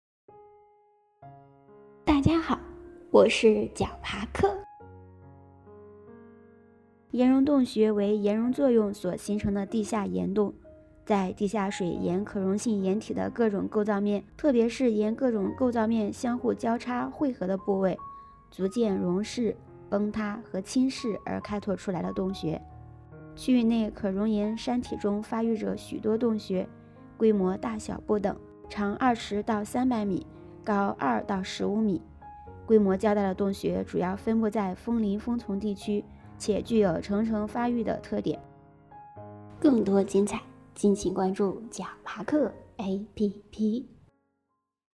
岩溶洞穴----- 豌豆荚 解说词: 岩溶洞穴为岩溶作用所形成的地下岩洞，为地下水沿可溶性岩体的各种构造面（层面、节理面或断层面），特别是沿各种构造面相互交叉、汇合的部位，逐渐溶蚀、崩塌和侵蚀而开拓出来的洞穴。